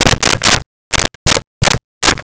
Telephone Dial 02
Telephone Dial 02.wav